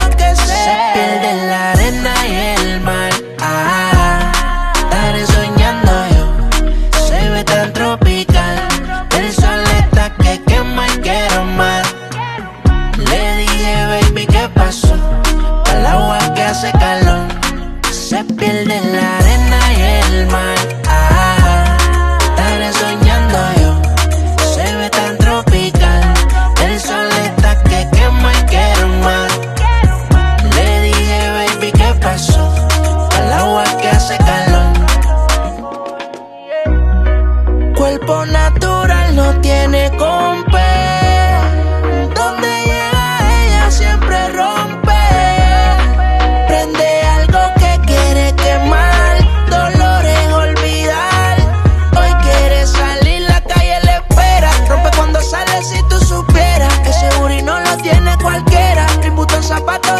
tiktok funny sound hahaha